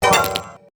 UI_SFX_Pack_61_23.wav